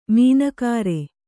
♪ mīna kāre